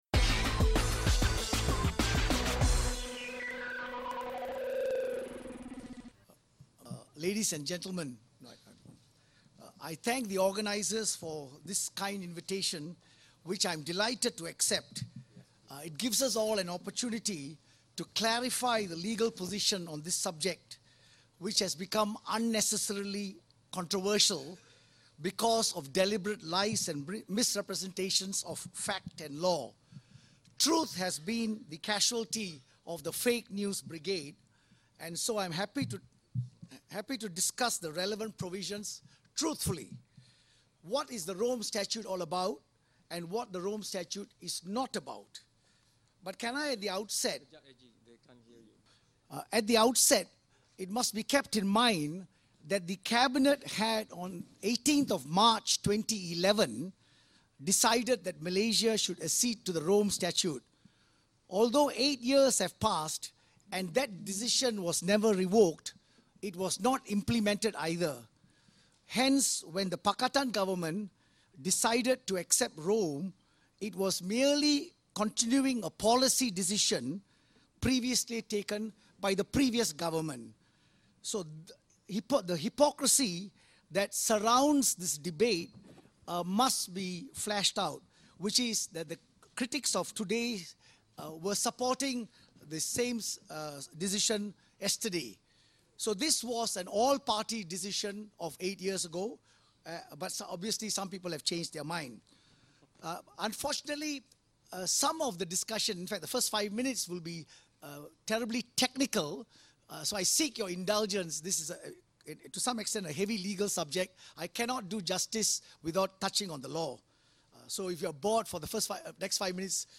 Statut Rom : Ucapan penuh Peguam Negara Tommy Thomas
Ikuti ucapan penuh Peguam Negara Tommy Thomas di Forum Malaysia & Statut Rom di Universiti Malaya pada Sabtu (27 April 2019).